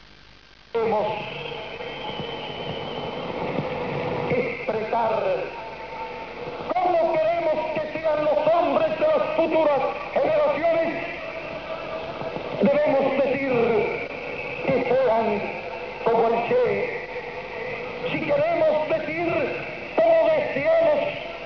Fidel i en tale efter Ches død